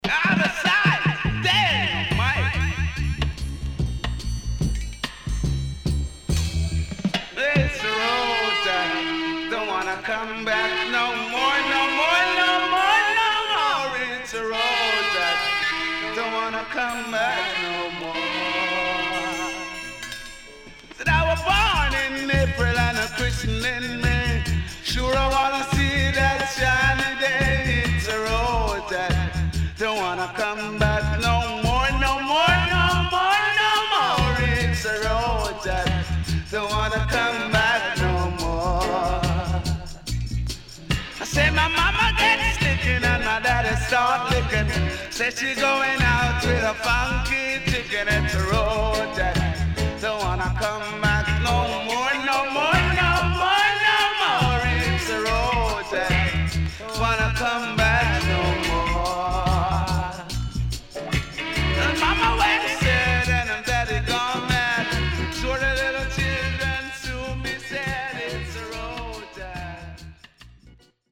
HOME > LP [VINTAGE]  >  70’s DEEJAY  >  RECOMMEND 70's